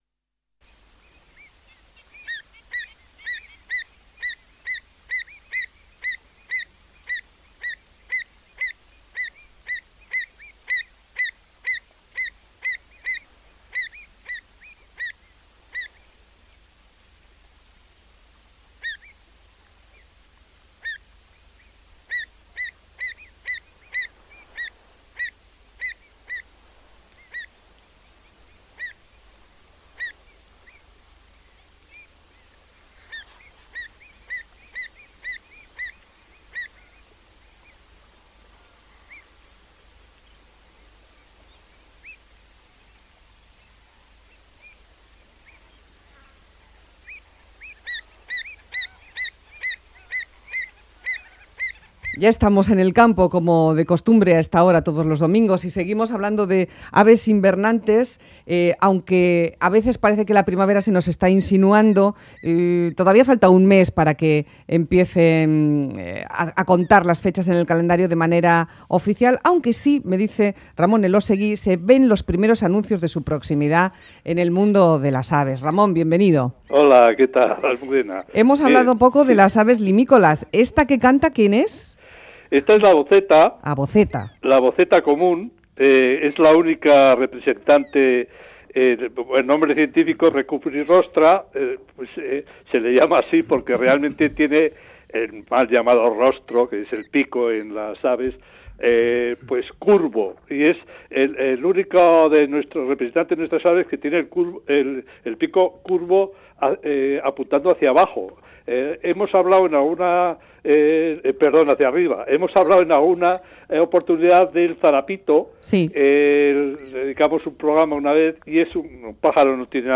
La avoceta